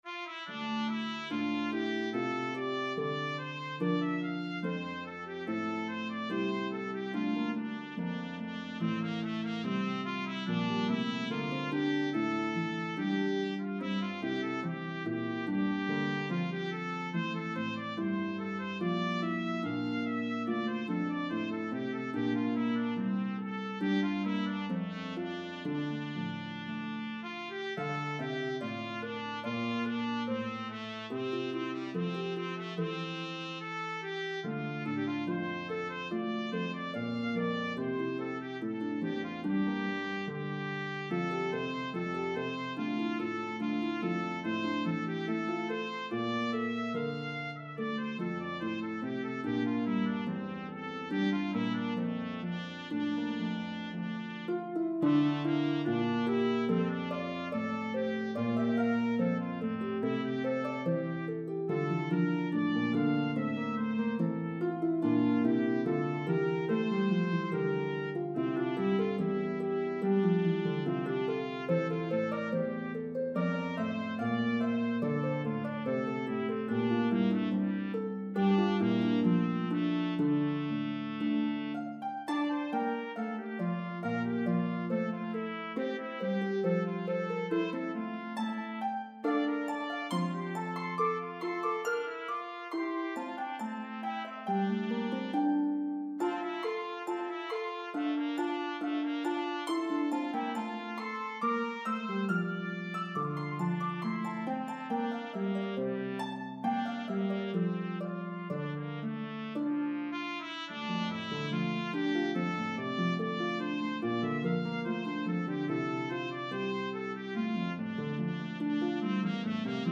This beautiful Aeolian melody has a debated history.